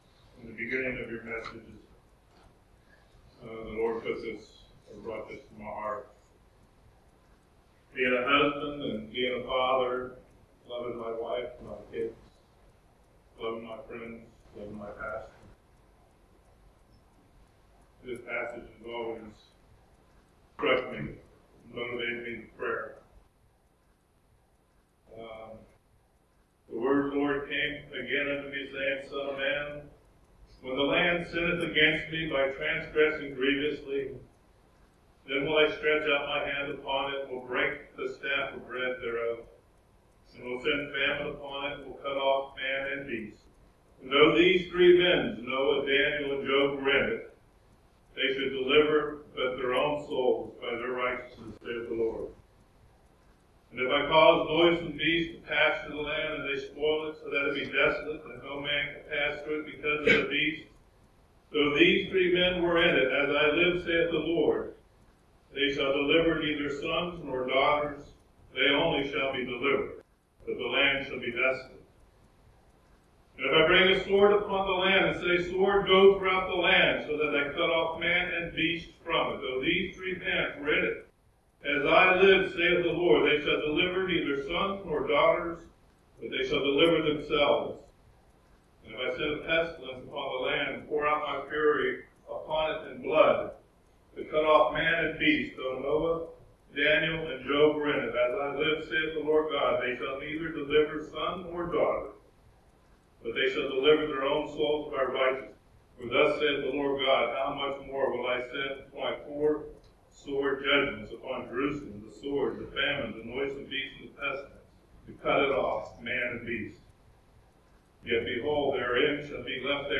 A message the Lord gave me to share in open assembly.